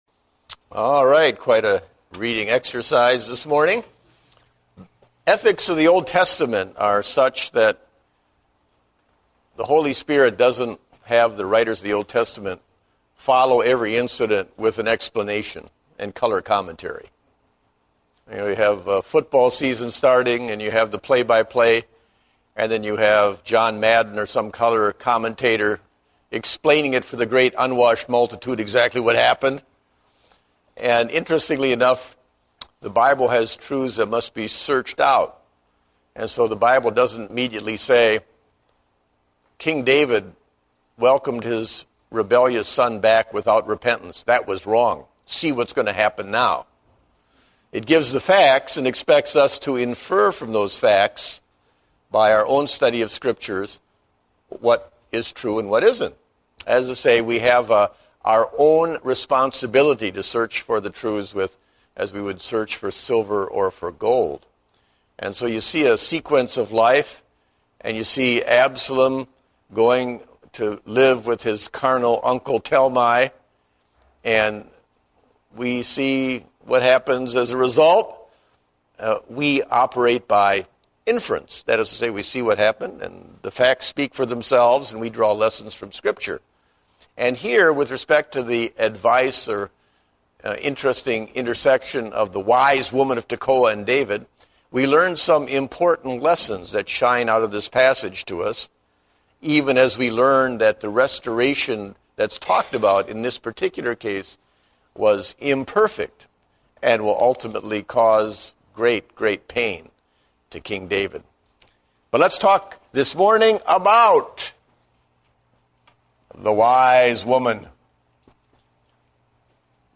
Date: August 22, 2010 (Adult Sunday School)